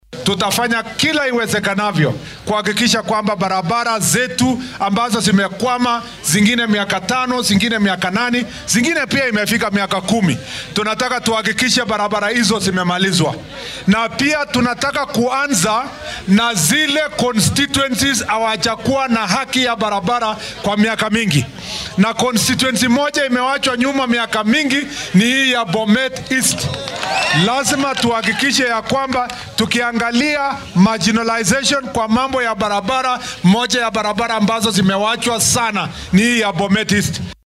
Wasiirka gaadiidka ayaa arrintan ka hadlay xilli uu kormeerayay waddooyin laga hirgelinaya deegaanka Chepalungu ee ismaamulka Bomet.